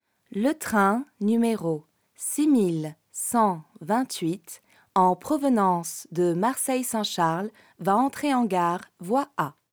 Voix off
20 - 45 ans - Mezzo-soprano